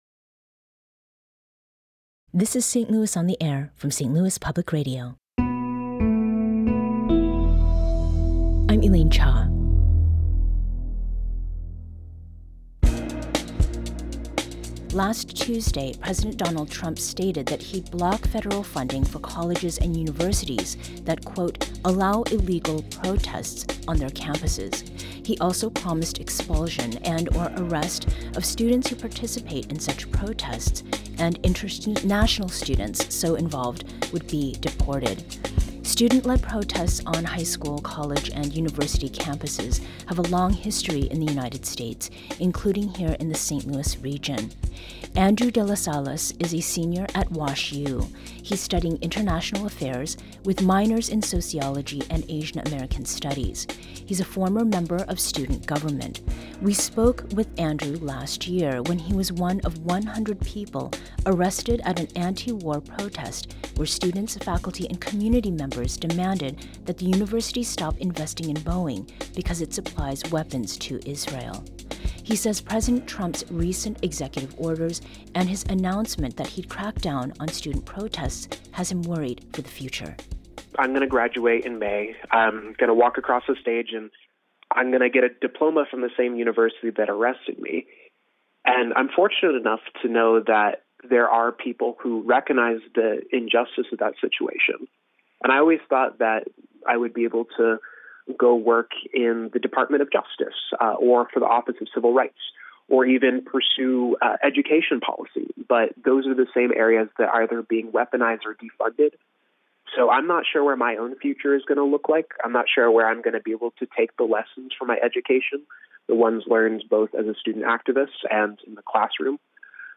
We hear from St. Louis college student activists who are focused on building community and protections for actions to come